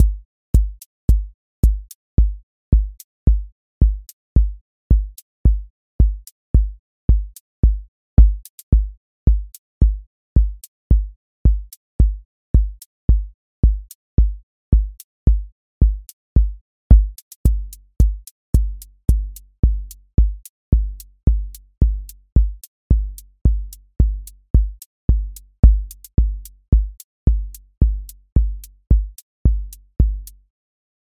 Four Floor Drive QA Listening Test house Template: four_on_floor April 17, 2026 ← Back to all listening tests Audio Four Floor Drive
four on floor
voice_kick_808 voice_hat_rimshot voice_sub_pulse